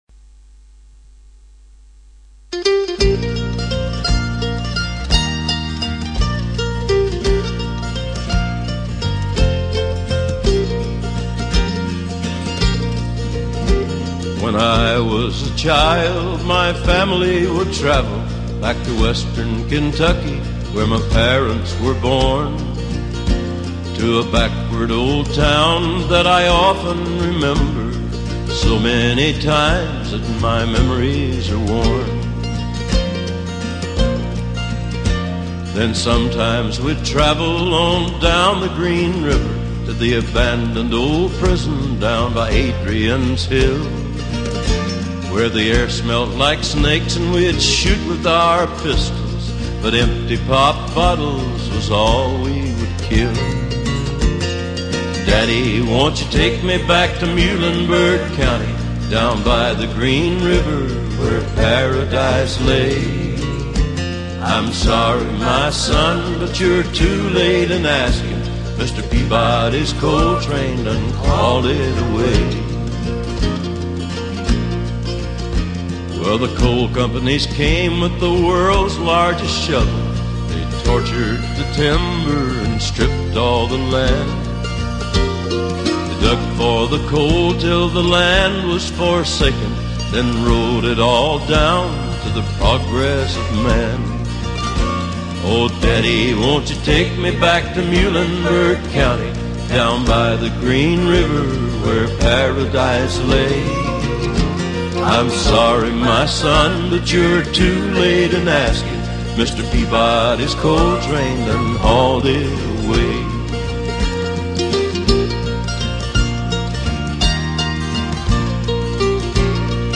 Жанр: Blues, Folk, World, & Country